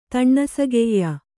♪ taṇṇasageyya